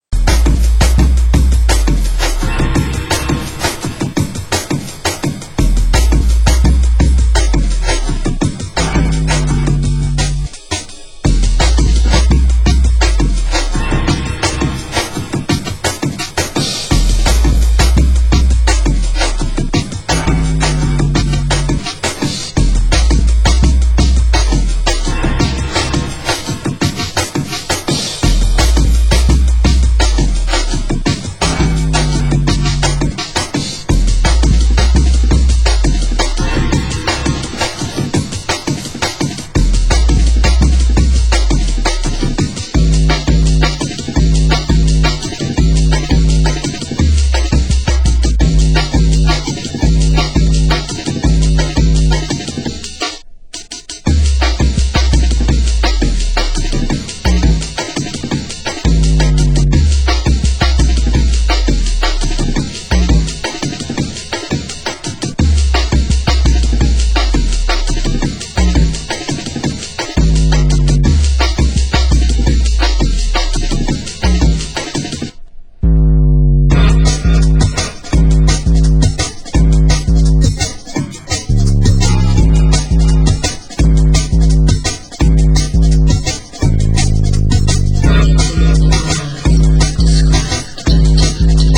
Genre: Drum & Bass